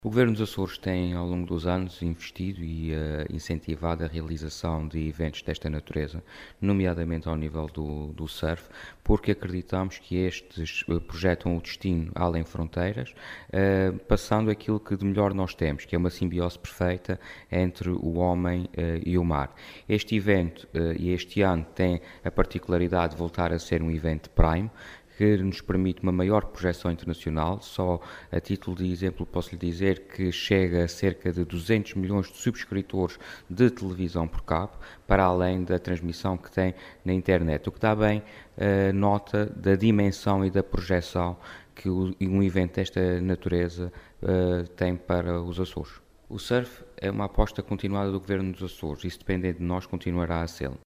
O Secretário Regional falava na praia de Santa Bárbara, na Ribeira Grande, onde fez a contagem decrescente e deu o "sinal de partida” para a etapa açoriana do circuito mundial de Surf, que este ano volta a ter estatuto 'Prime', adquirindo maior importância e atratividade para os surfistas e obtendo, também por essa via, maior visibilidade a nível mundial.